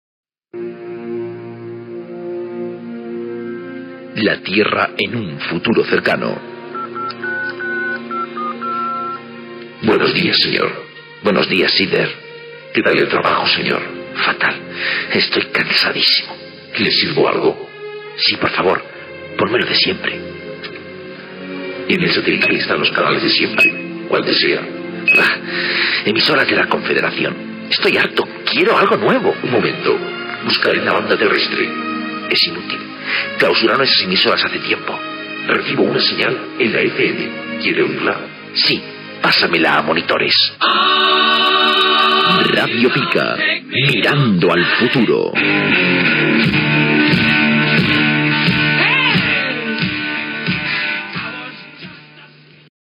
Promoció de la ràdio